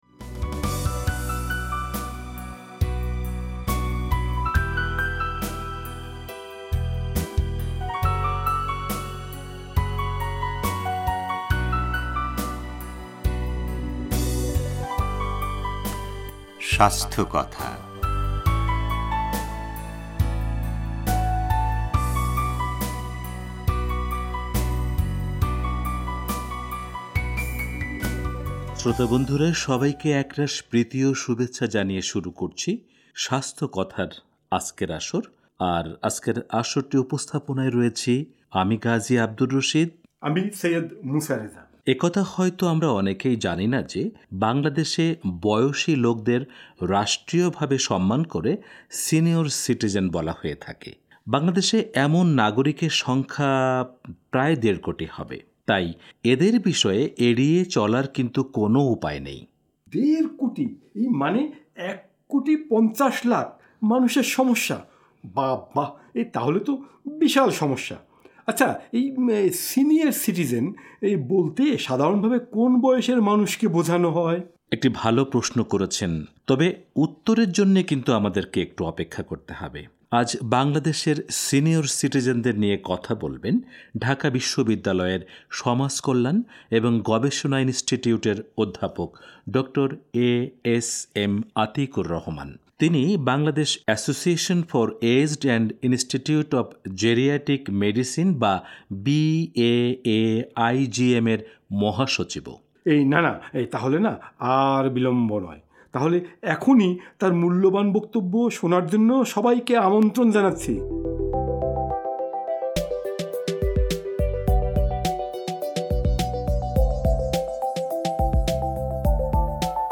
রেডিও তেহরানে সঙ্গে এ নিয়ে তিন পর্বে আলোচনা করেছেন